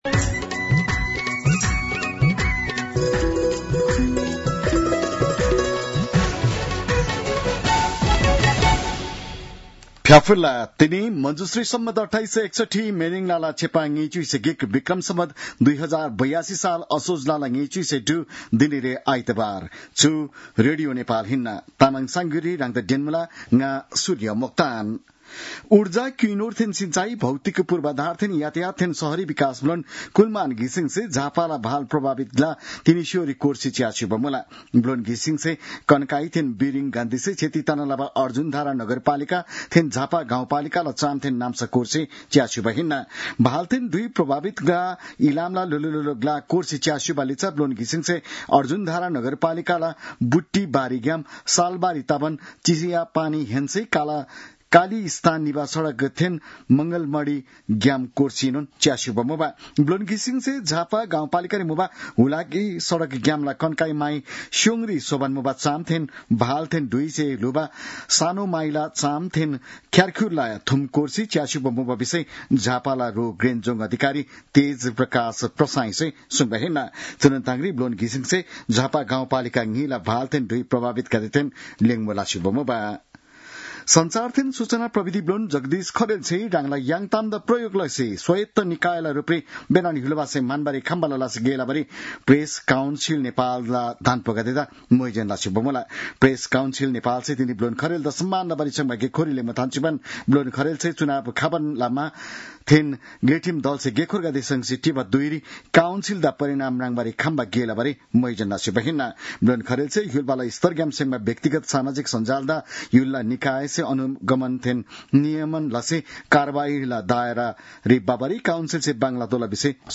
तामाङ भाषाको समाचार : २६ असोज , २०८२